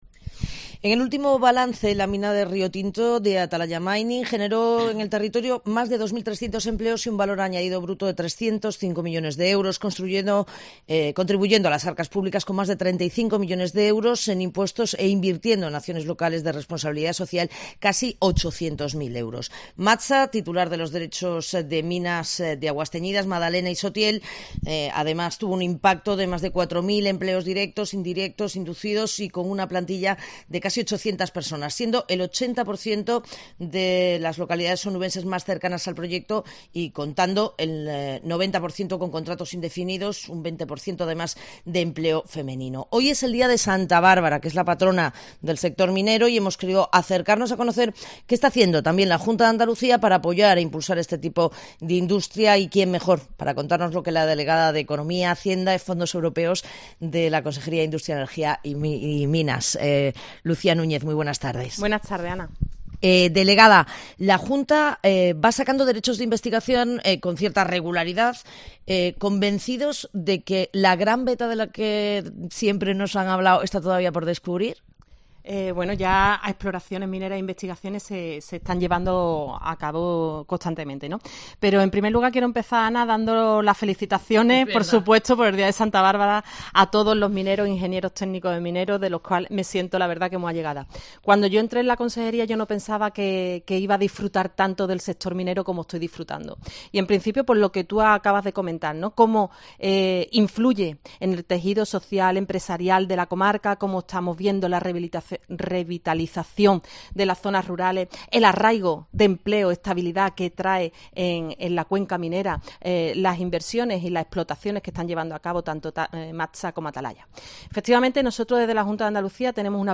En el día de Santa Bárbara, patrona de la minería, hablamos con la delegada Lucía Núñez que nos avanza el futuro más o menos inmediato del sector.